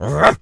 pgs/Assets/Audio/Animal_Impersonations/dog_large_bark_05.wav
dog_large_bark_05.wav